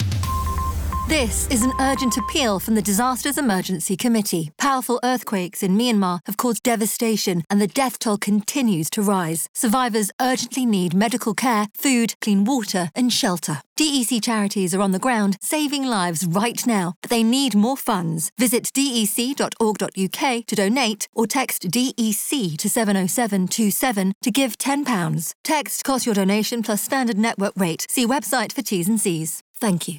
An appeal, voiced by Smooth Radio and Classic FM presenter, Myleene Klass, in response to the desperate situation in Myanmar following a powerful earthquake, has raised over £23m in donations.
Commercial and community stations, along with digital audio platforms, were provided 30, 20 and 10 second spots in support of the appeal.